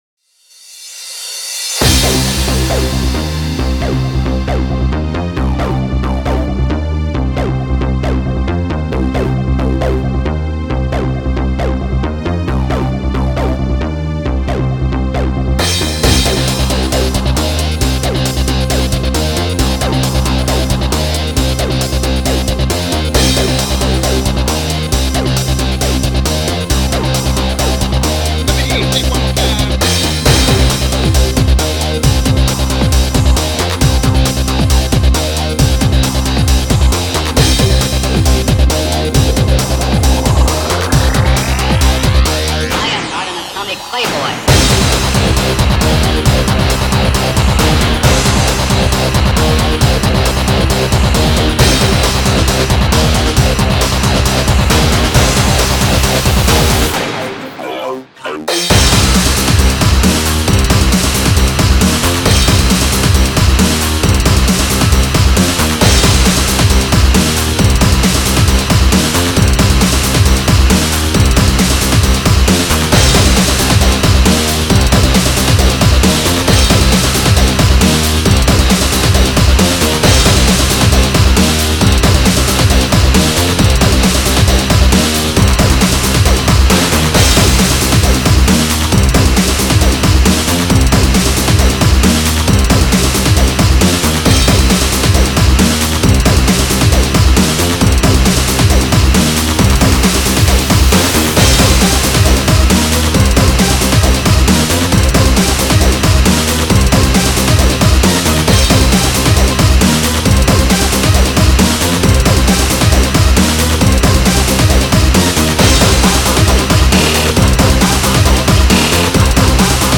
. this song is a big beat/breakbeat song but more modernized and sounds lik ...
like using guitar samples and distorted voice samples
like the background amen-break only difference are this song uses pendulum-style snares instead which makes it sound more modern
135 bpm made with fl